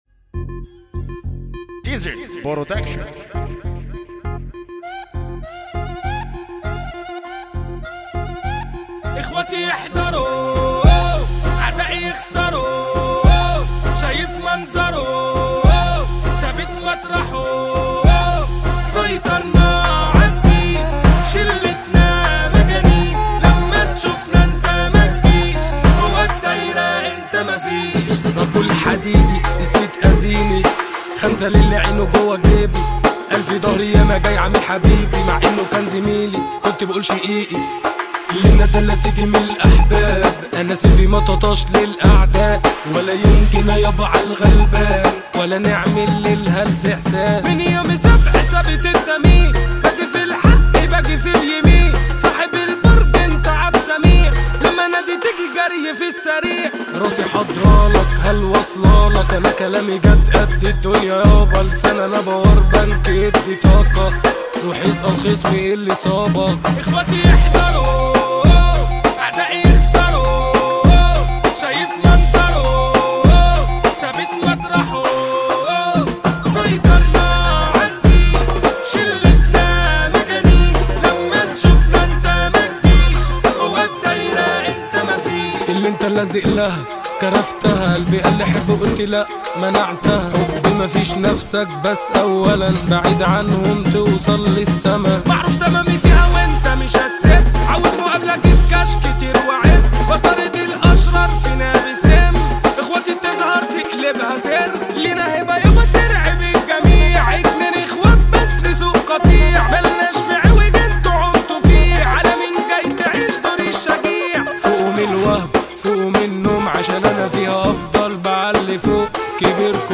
اغانى مهرجانات